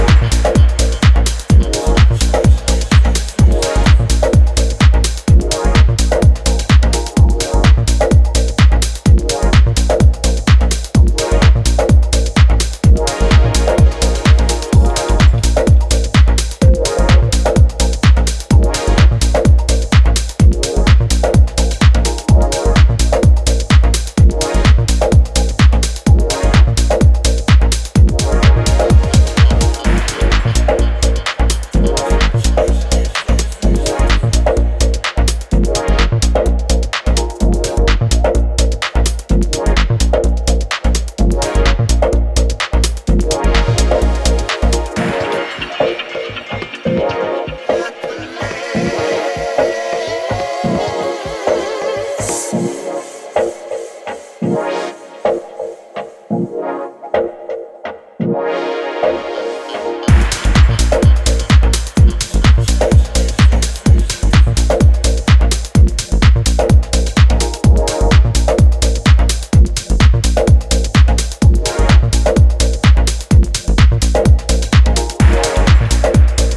club tools